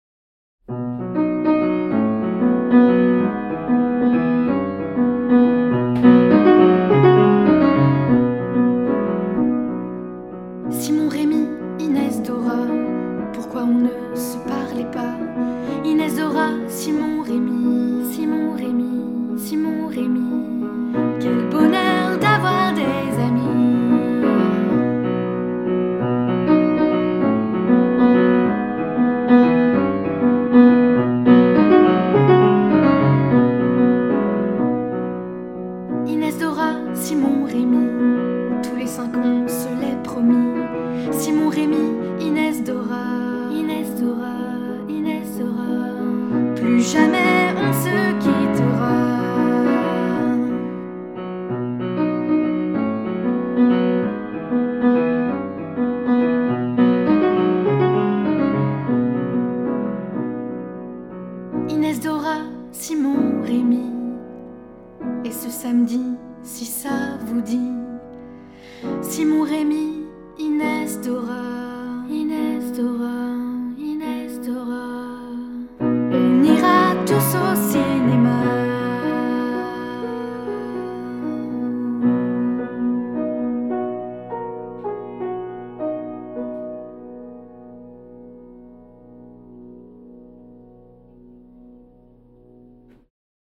Genre :  ChansonComptine
Effectif :  UnissonVoix égales
Audio Voix et Piano